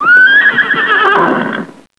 Horse 2
HORSE_2.wav